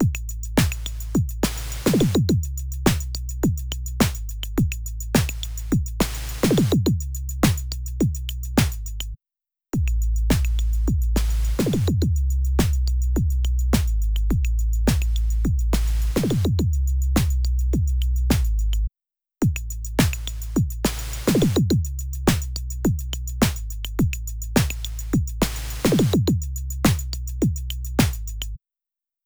他の帯域への悪影響を引き起こすことのない、低周波数の緻密かつ的確な形成